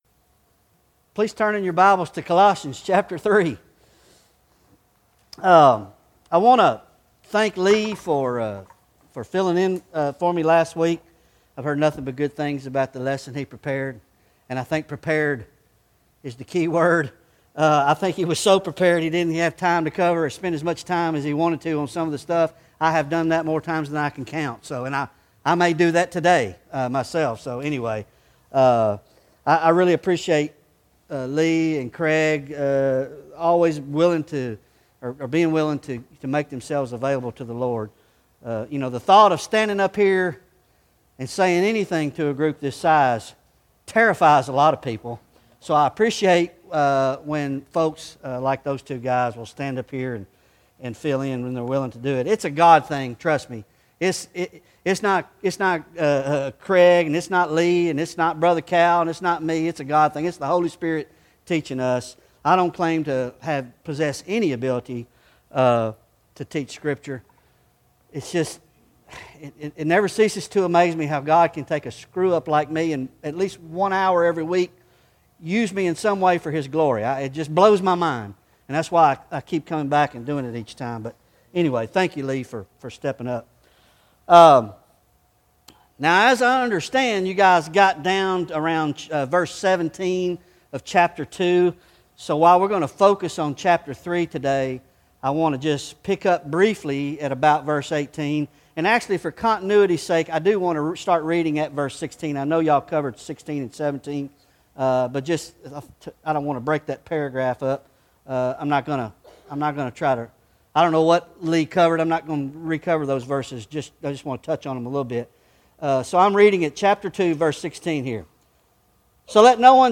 Bible Study Colossians Ch 3-4 III